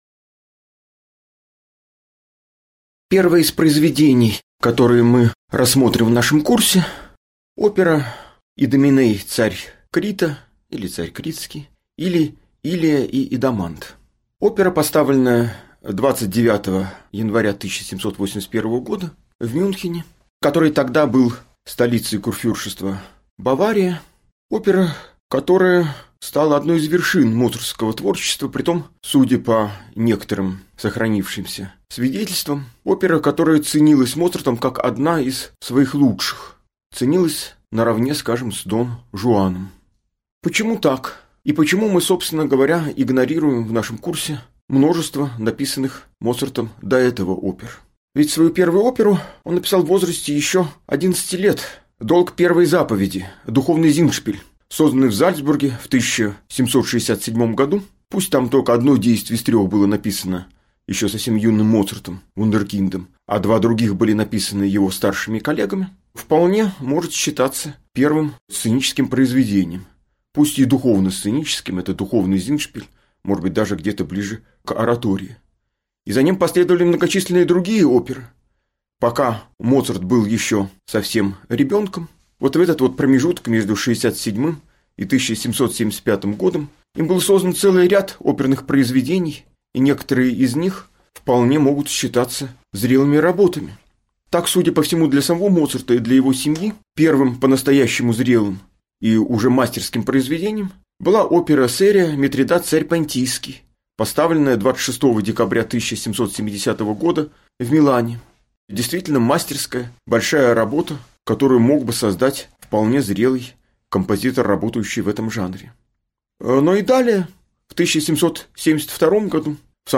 Aудиокнига Лекция «Идоменей, царь Критский».